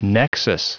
Prononciation du mot nexus en anglais (fichier audio)
Prononciation du mot : nexus